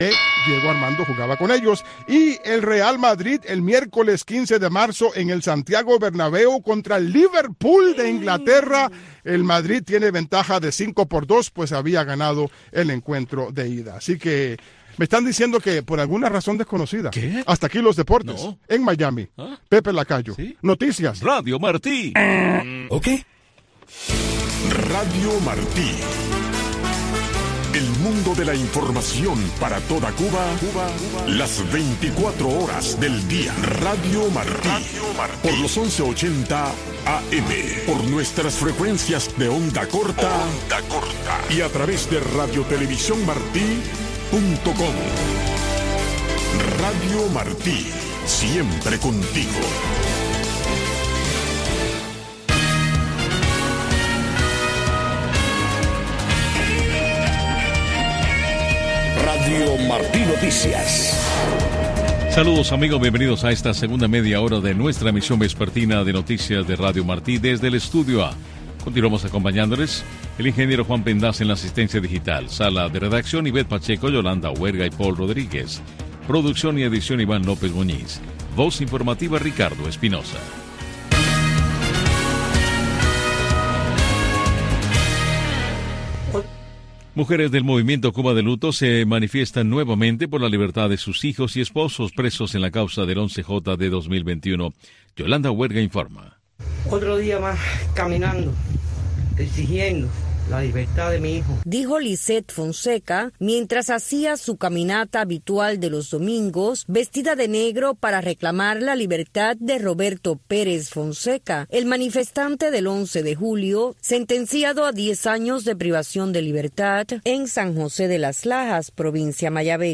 Noticiero Radio Martí presenta los hechos que hacen noticia en Cuba y el mundo